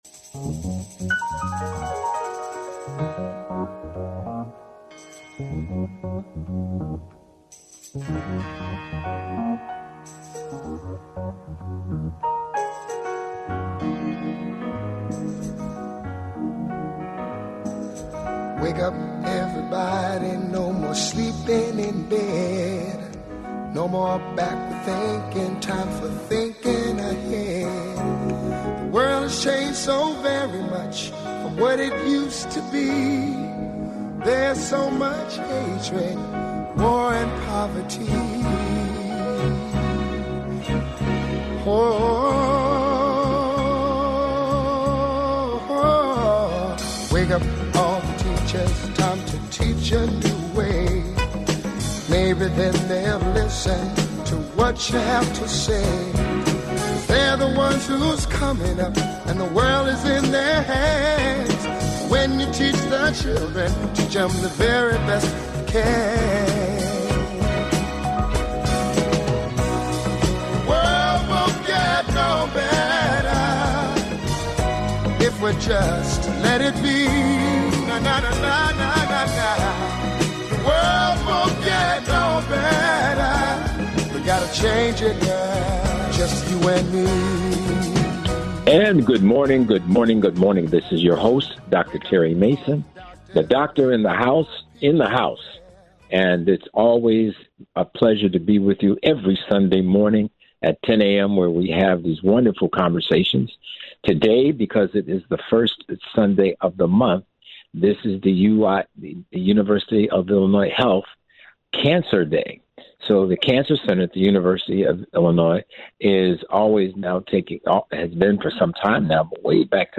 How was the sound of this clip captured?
The show aired live May 5.